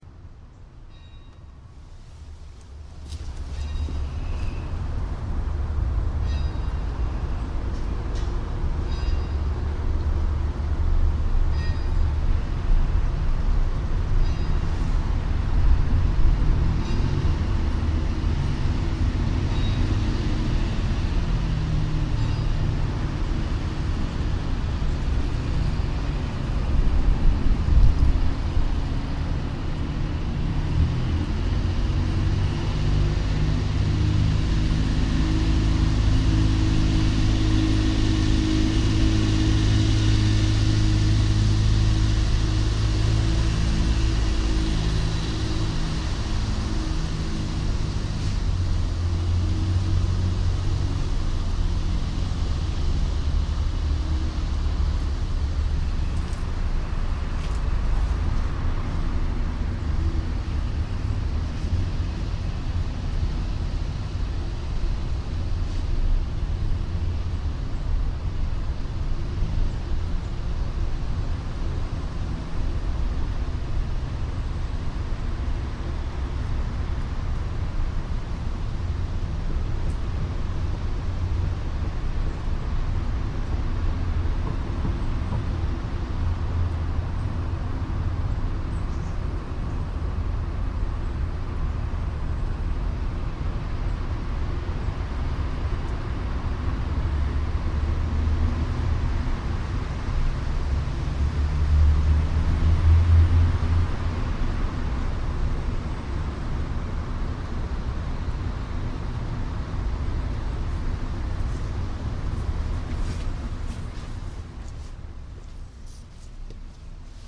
Scottish National Gallery of Modern Art, Dean Gallery garden: Dan Graham, Two Two-Way Mirrored Parallelograms Joined with One Side Balanced Spiral Welded Mesh, 1996. 24th September 2007